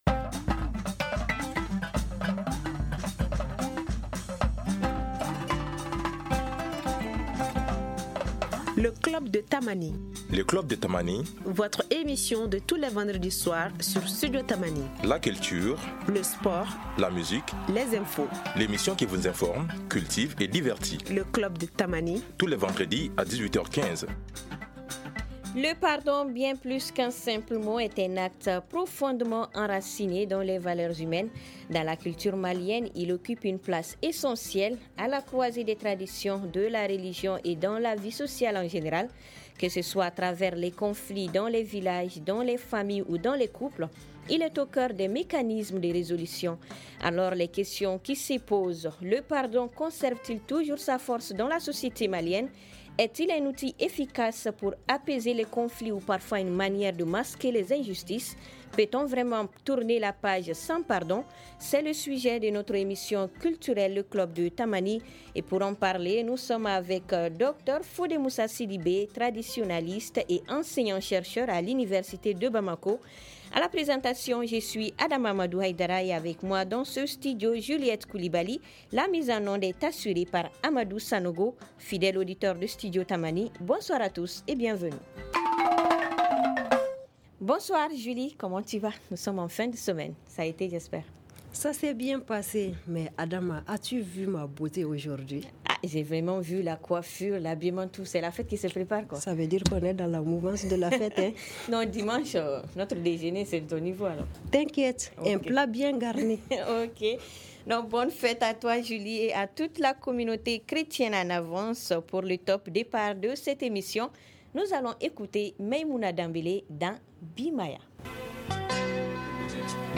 Studio Tamani pose le débat avec comme invité